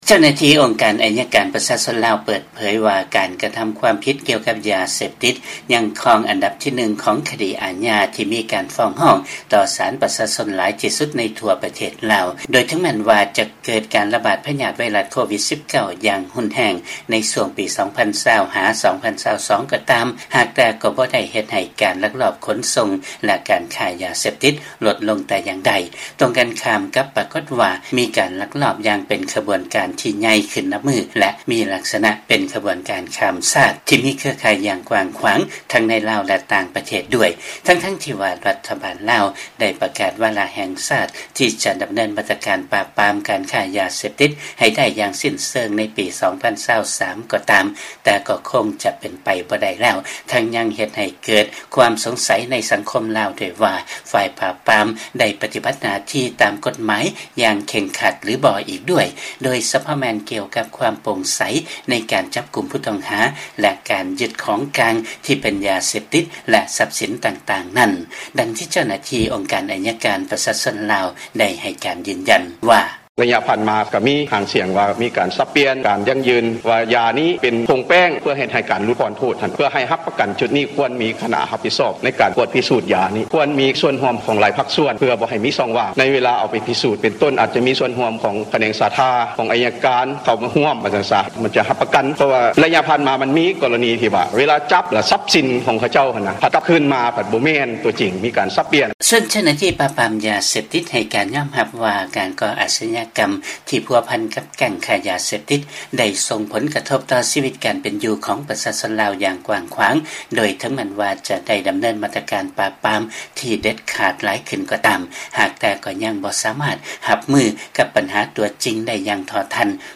ເຊີນຮັບຟັງ ລາຍງານກ່ຽວກັບຝ່າຍປາບປາມຍາເສບຕິດຂອງທາງການລາວ ຈະຕ້ອງປັບປຸງລະບົບການປະຕິບັດງານໃຫ້ໂປ່ງໃສ ຢ່າງແທ້ຈິງ